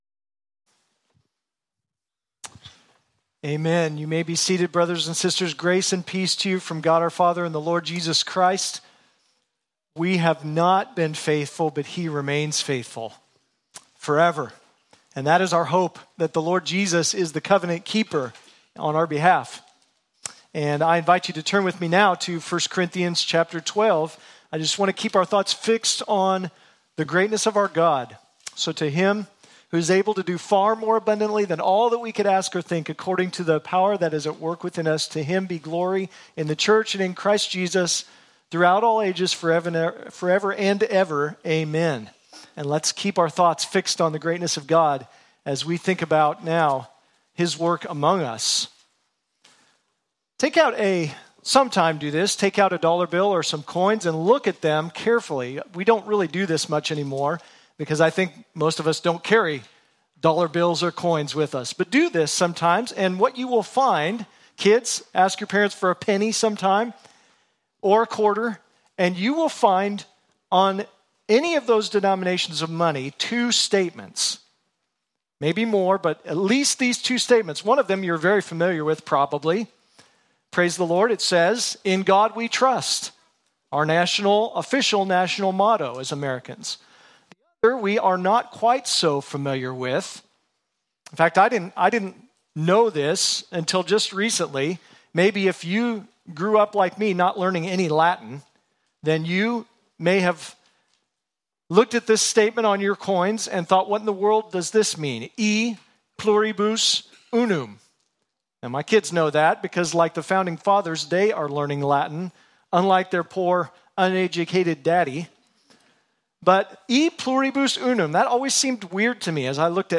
Sermons | Redemption Bible Church